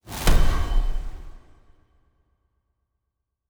Special Click 15.wav